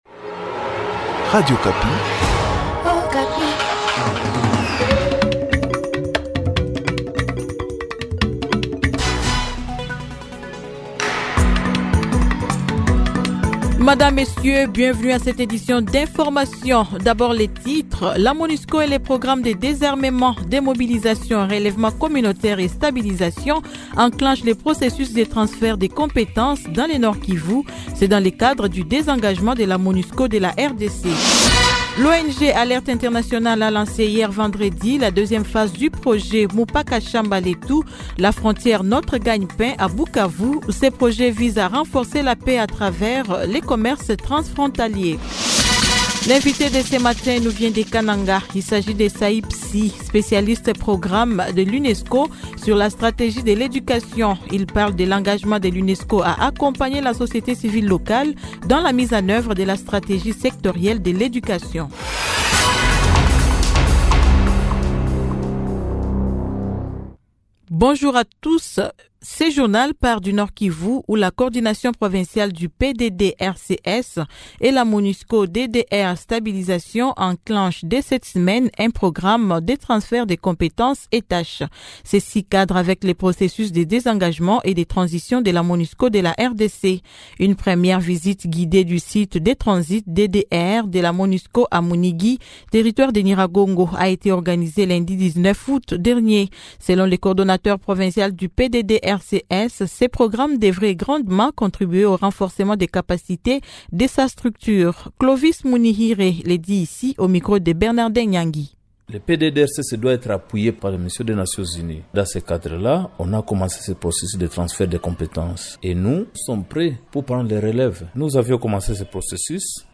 Journal matin 07H-08H